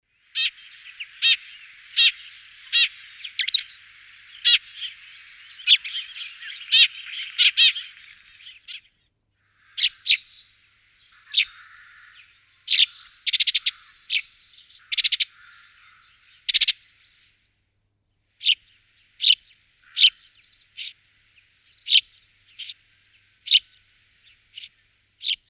Mattugia – Orto botanico
mattugia.mp3